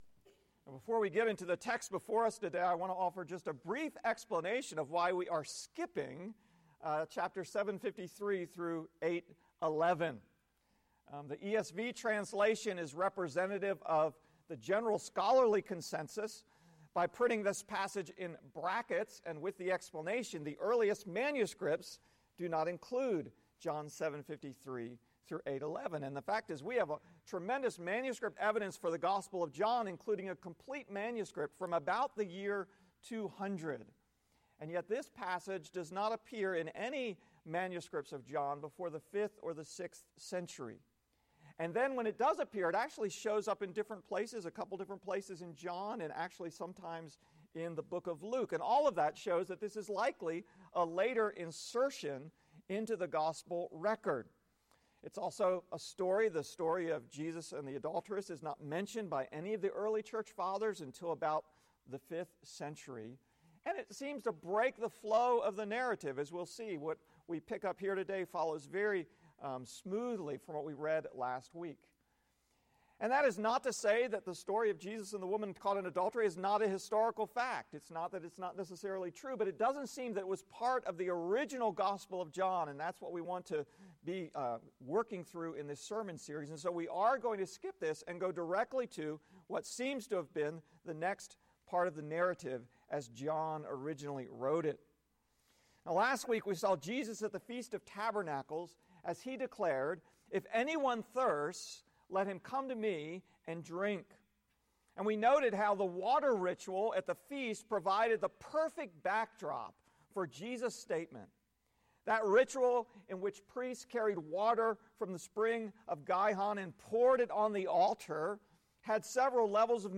Sermon-3-18-18.mp3